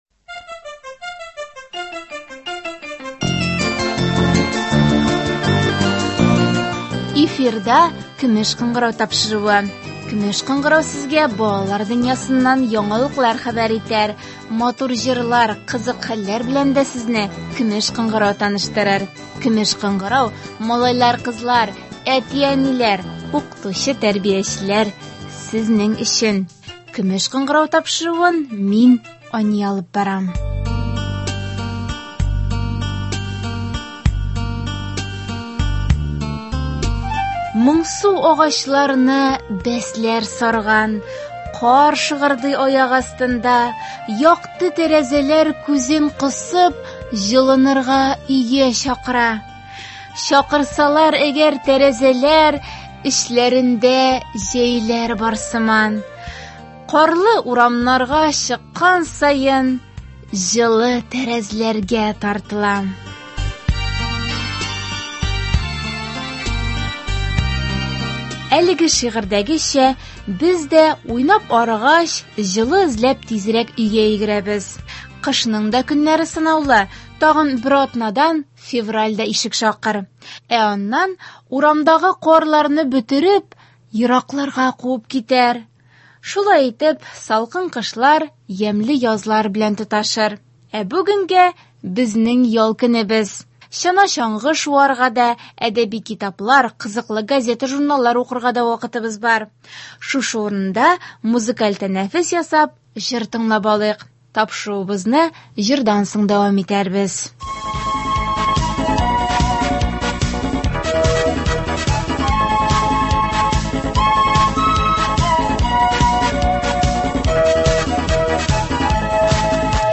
Бүген исә сәнгатьле сөйләм буенча үзләрен танытырга өлгергән кайбер дусларыбызның чыгышларын ишетербез.